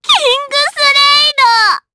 Pansirone-Vox_Kingsraid_jp_b.wav